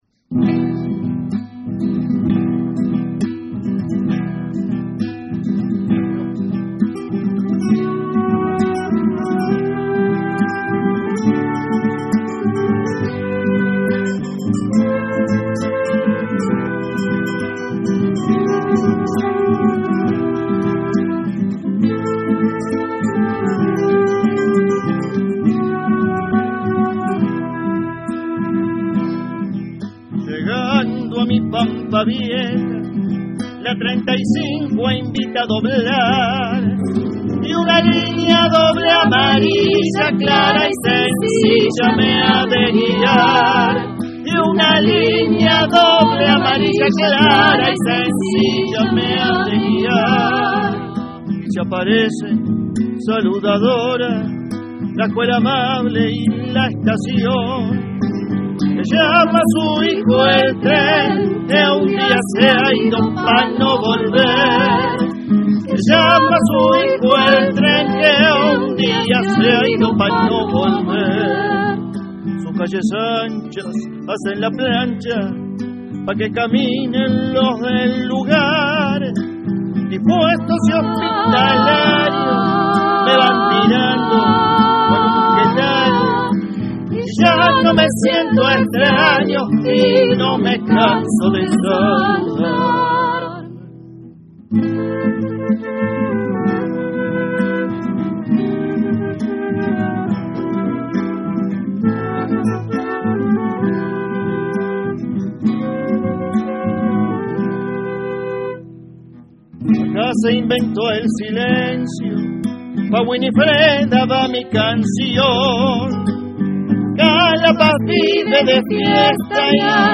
flauta traversa
guitarra